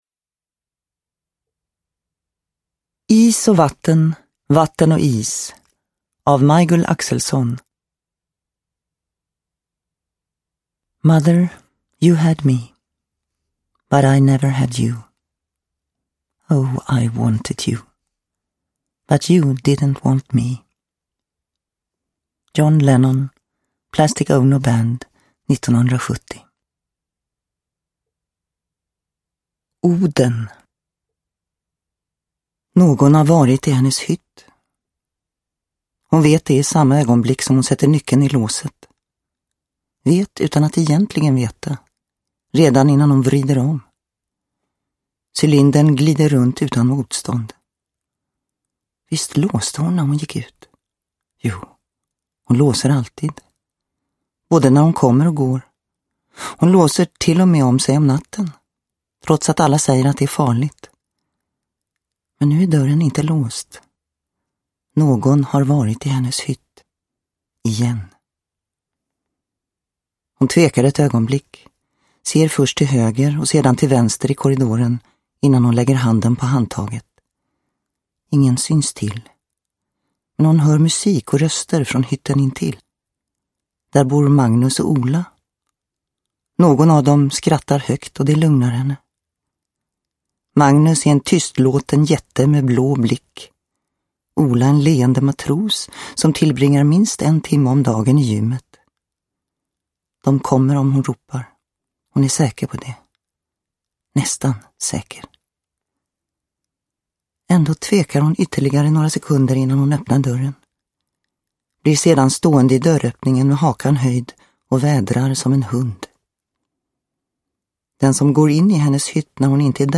Is och vatten, vatten och is – Ljudbok – Laddas ner